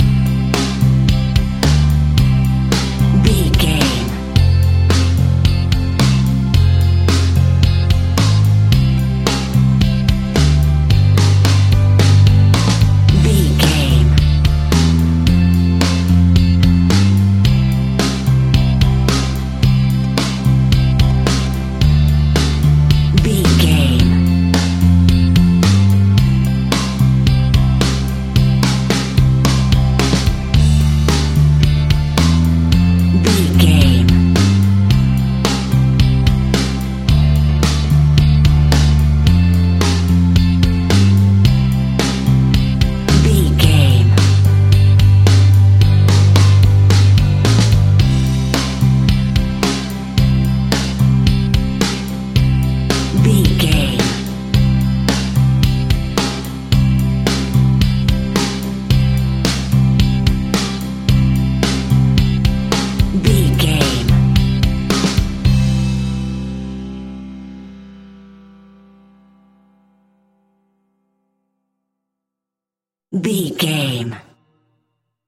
Ionian/Major
calm
melancholic
happy
energetic
smooth
uplifting
electric guitar
bass guitar
drums
pop rock
indie pop
instrumentals
organ